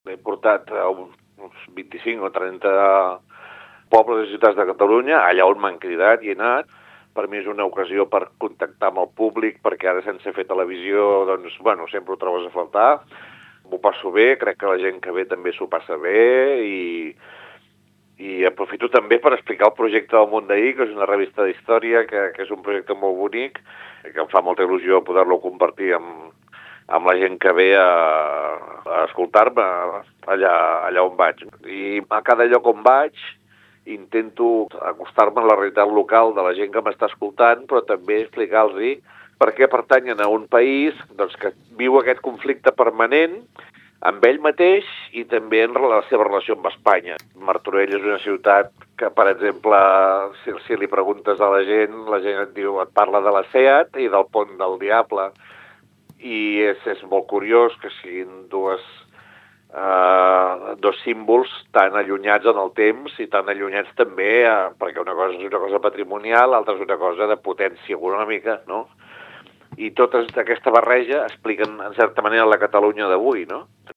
Toni Soler, monologuista de ‘Per què Catalunya no és una potència mundial?’